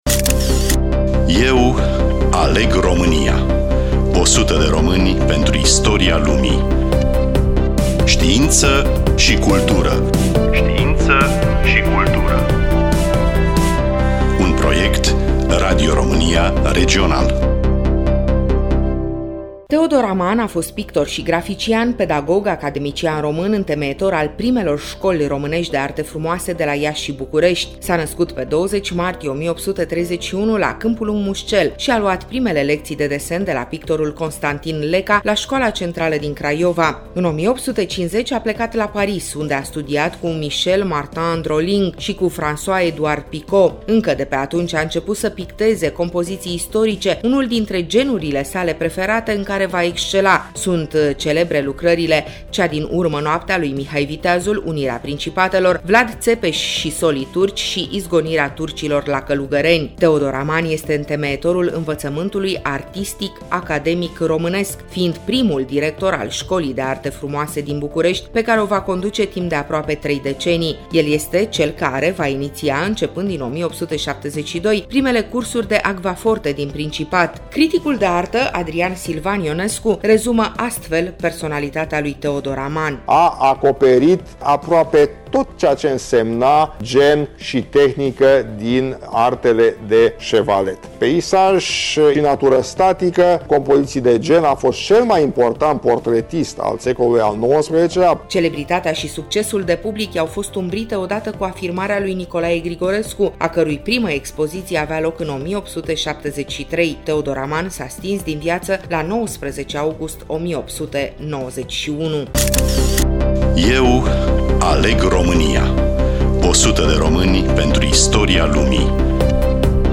Studioul: Radio Romania Oltenia-Craiova